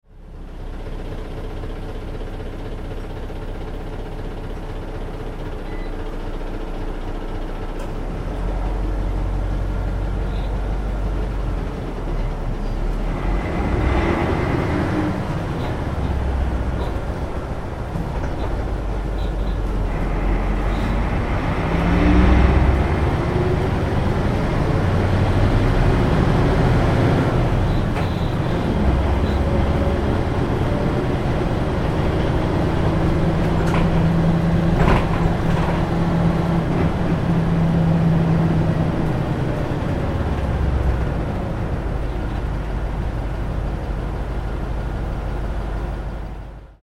「キュービック」ツーステップ車両としては最終形態。 最大の特徴は、路線車としては異色の「ドドドド・・・」というV8エンジンサウンド。
走行音(46秒・914KB)